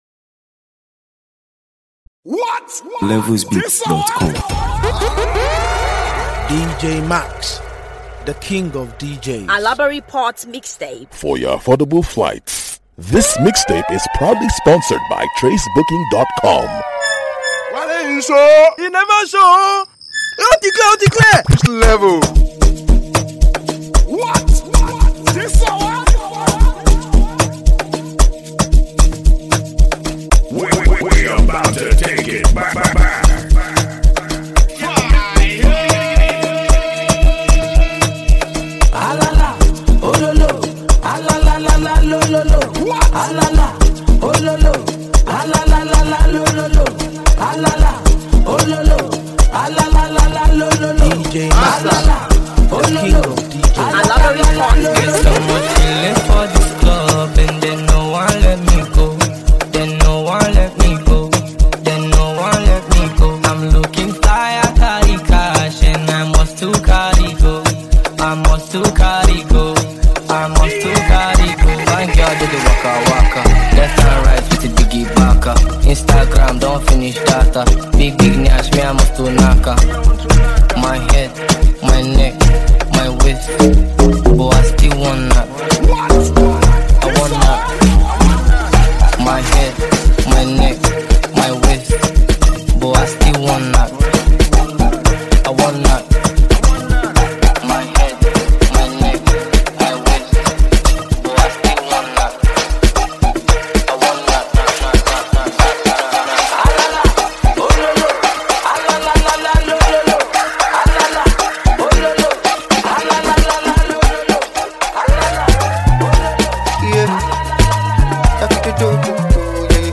Mp3 Download African songs